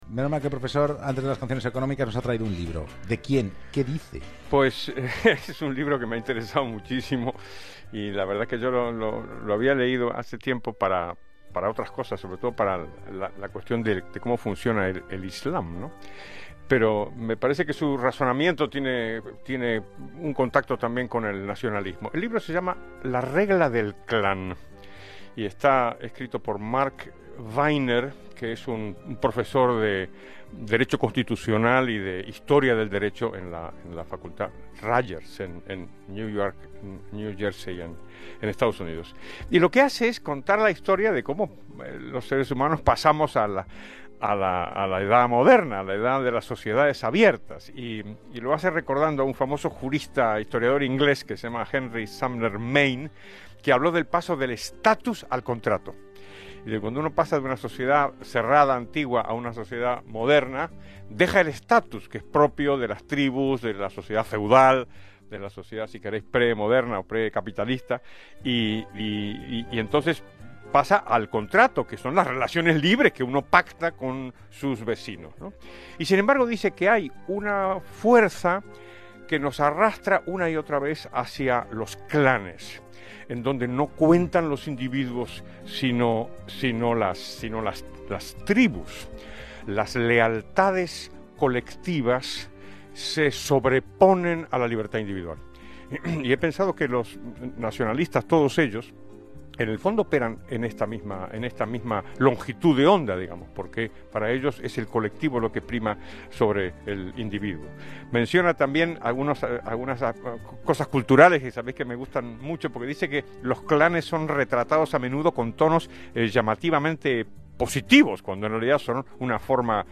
d709a-spanish-radio-2.mp3